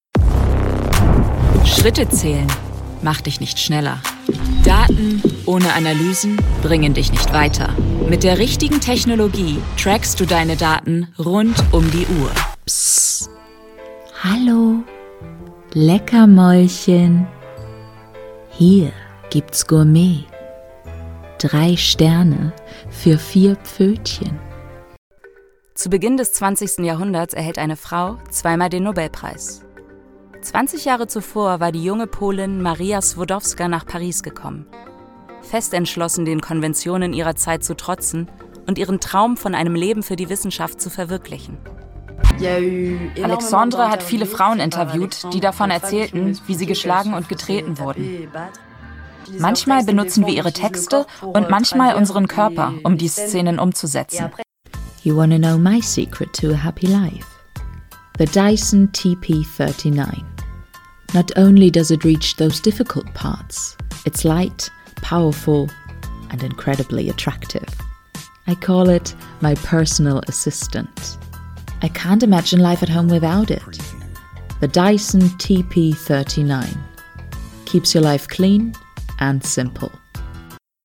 Commercial Demo
I specialize in bringing characters, commercials, and narrations to life with a warm, engaging, and versatile voice.
I work from my professional home studio, ensuring high-quality recordings, fast turnaround times, and a flexible, reliable service.
LowMezzo-Soprano